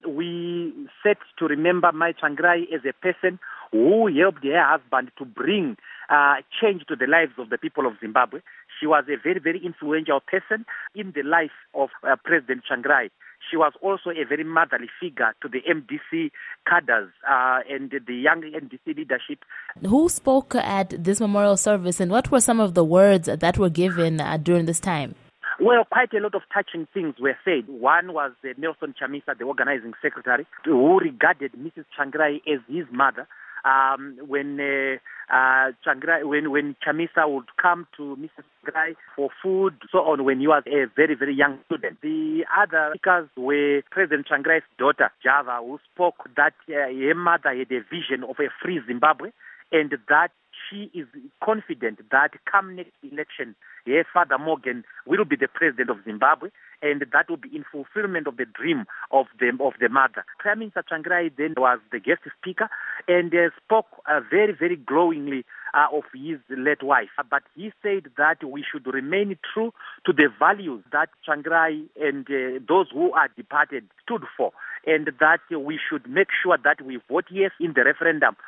Interview With Douglas Mwonzora